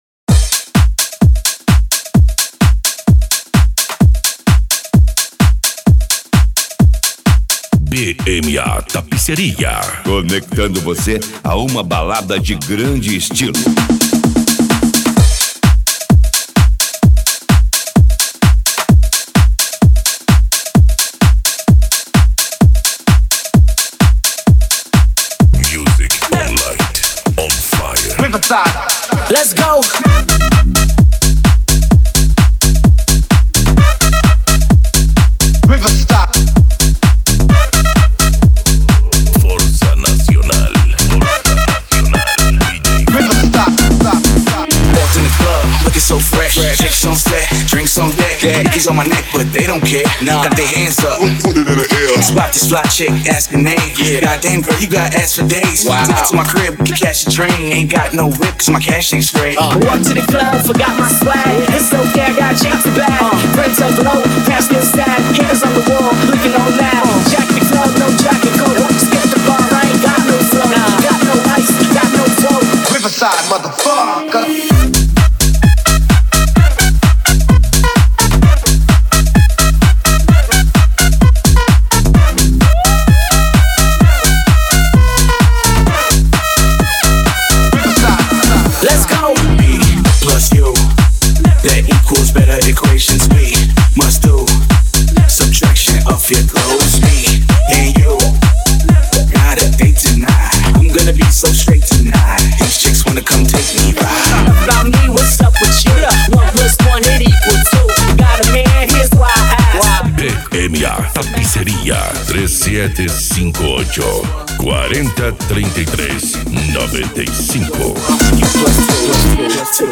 Eletronica
Musica Electronica
Psy Trance